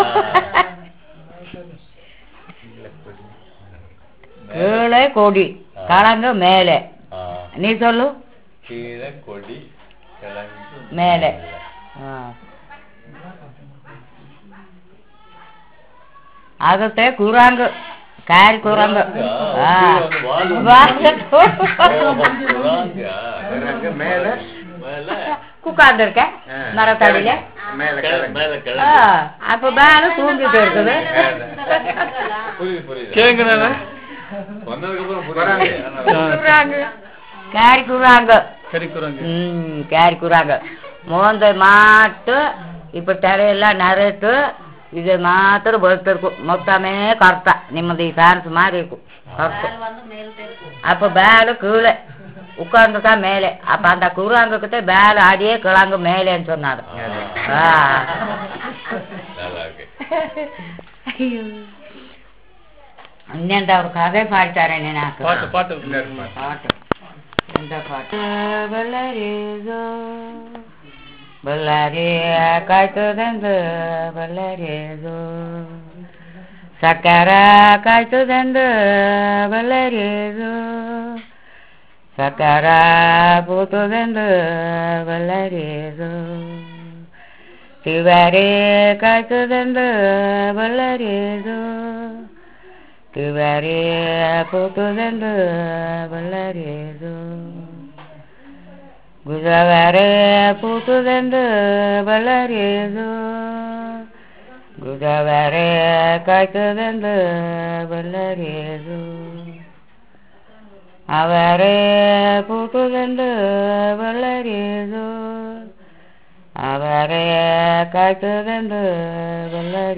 Performance of a traditional song